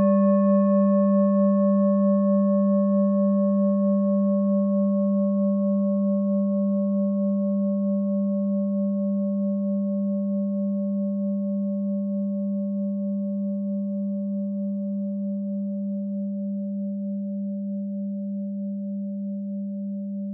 Klangschale Bengalen Nr.8
Klangschale-Gewicht: 1340g
Klangschale-Durchmesser: 21,2cm
Sie ist neu und wurde gezielt nach altem 7-Metalle-Rezept in Handarbeit gezogen und gehämmert.
(Ermittelt mit dem Filzklöppel)
Auf unseren Tonleiter entspricht er etwa dem "G".
klangschale-ladakh-8.wav